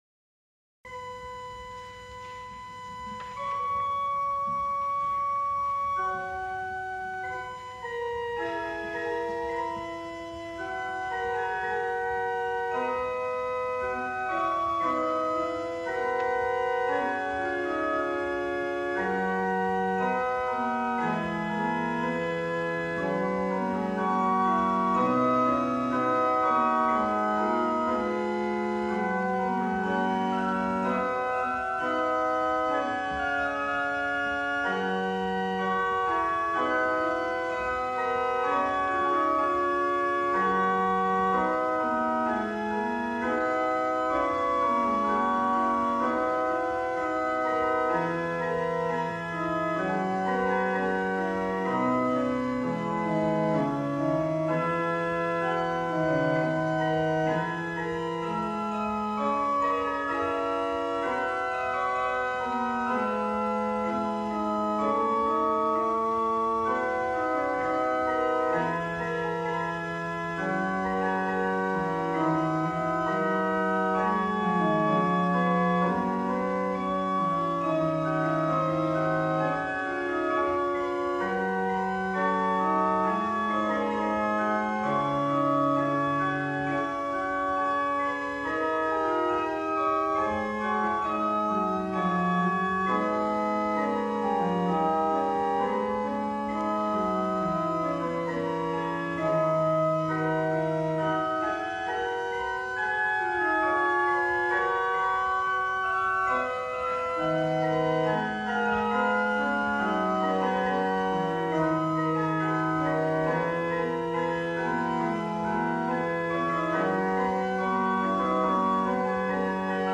Ricercar
organ